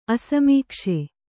♪ asamīkṣe